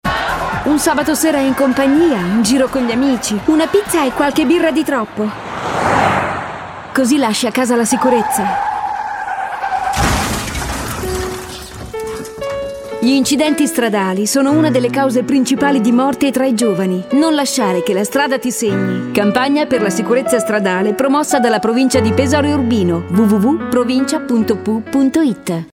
Spot radiofonico